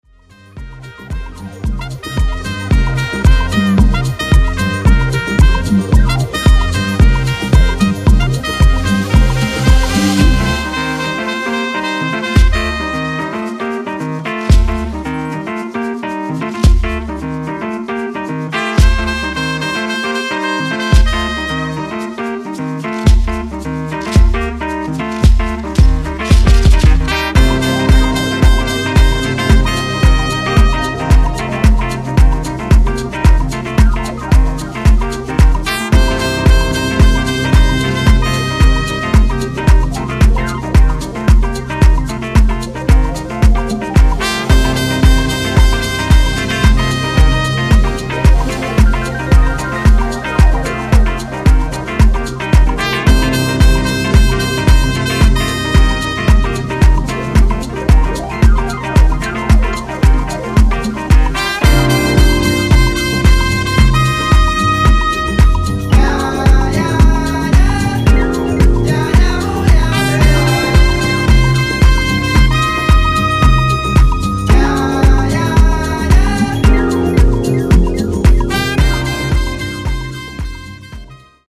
ジャンル(スタイル) DEEP HOUSE / JAZZ HOUSE / BROKEN BEAT,